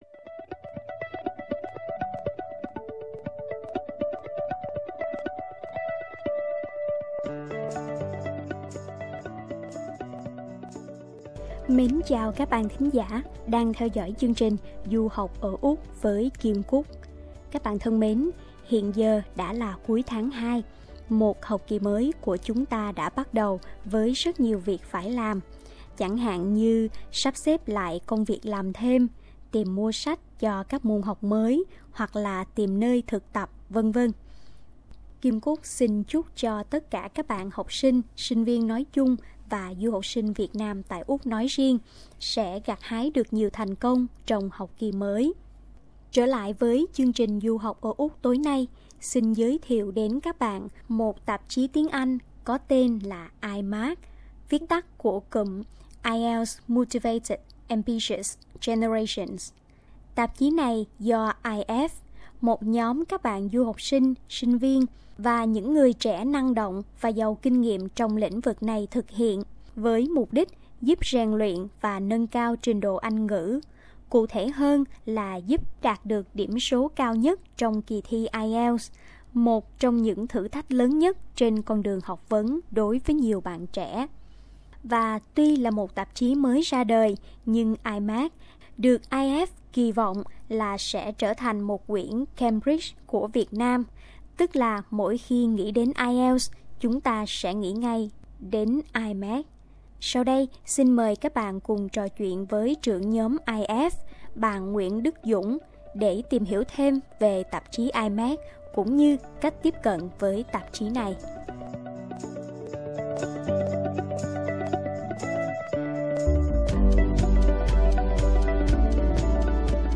SBS Việt ngữ